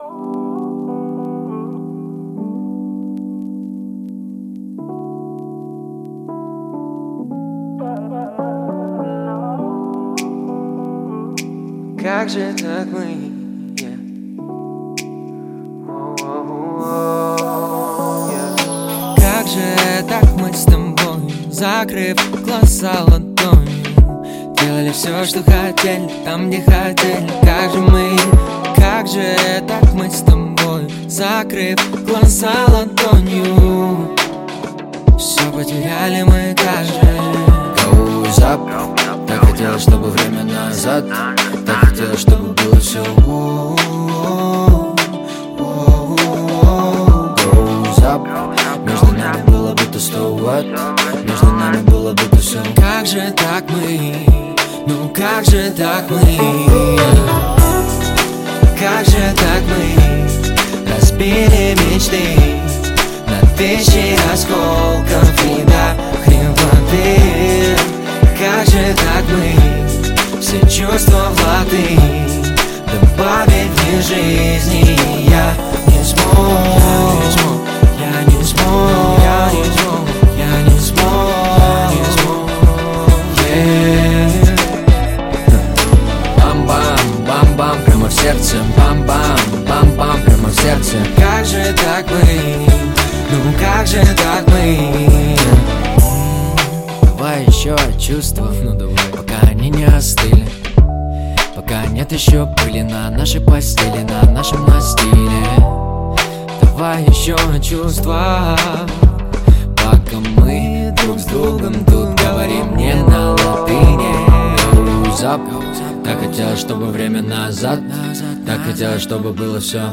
Жанр: Русский рэп / R & B